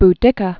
(b-dĭkə) also Bo·ad·i·ce·a (bōăd-ĭ-sēə) First century AD.